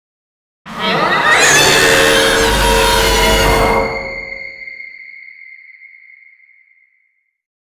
NPC_Creatures_Vocalisations_Robothead [94].wav